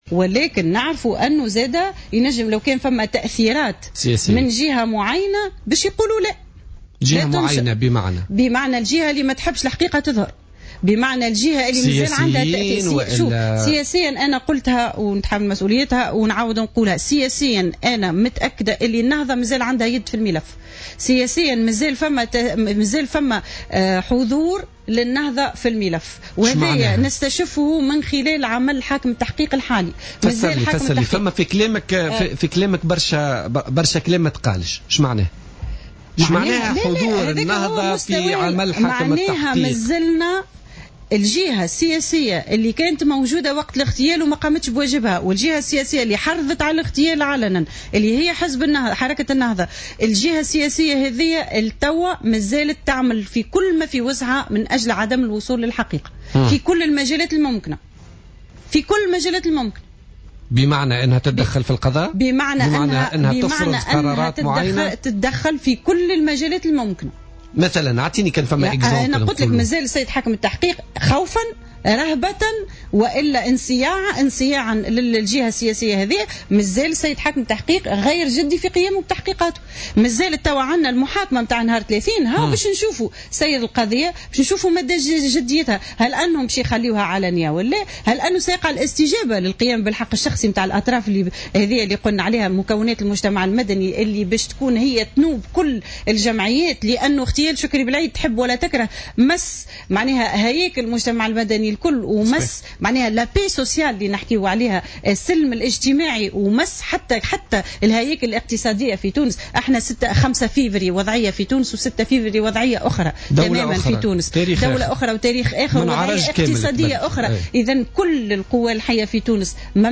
قالت بسمة الخلفاوي أرملة شكري بلعيد ضيفة برنامج "بوليتيكا" اليوم الاربعاء إن حركة النهضة مازالت تقوم بكل ما بوسعها لعدم التوصل لحقيقة اغتيال شكري بلعيد.